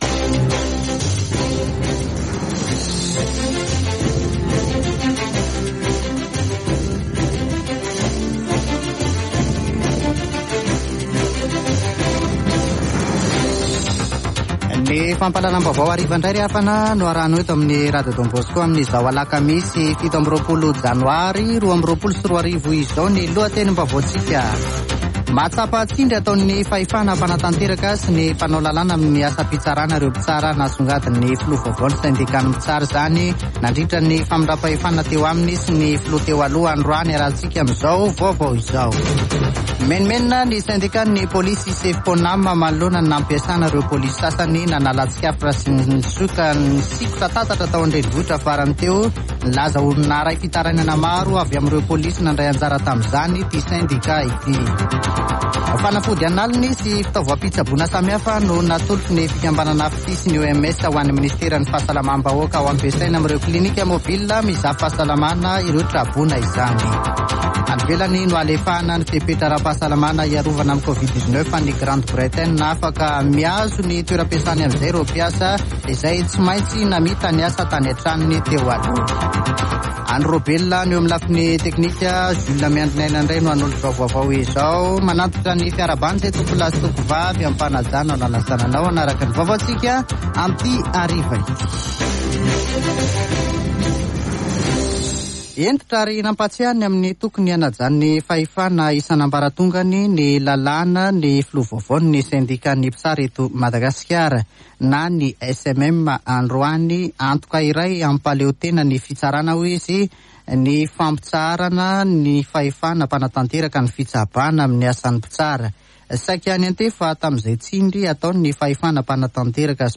[Vaovao hariva] Alakamisy 27 janoary 2022